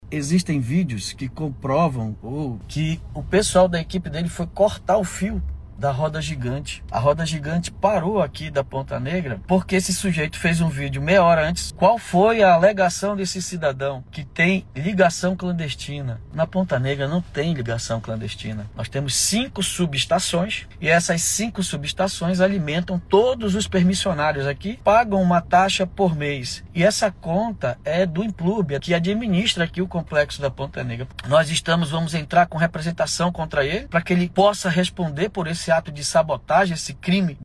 O prefeito de Manaus, David Almeida, entrou ao vivo nas redes sociais e declarou que a equipe do ex-vereador Amauri Gomes esteve no local para desligar a energia da estrutura. Ele também afirmou que o episódio passará por investigação.